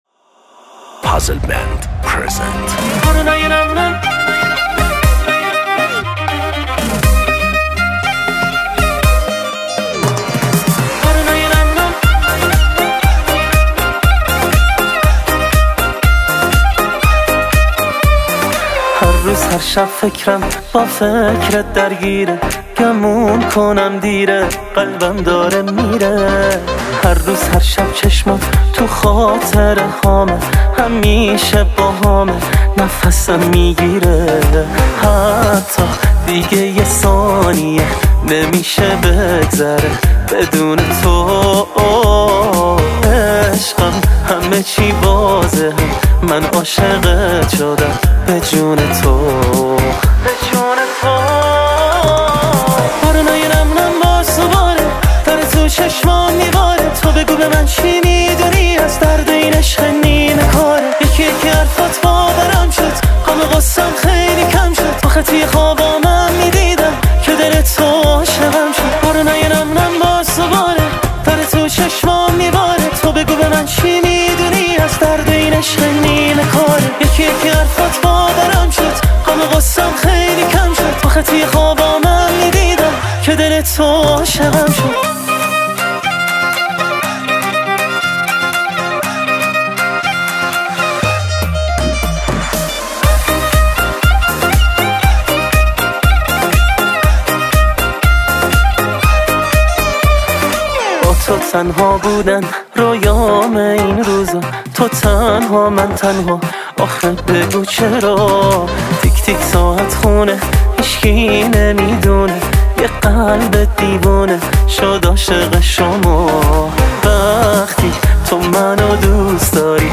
دیس لاو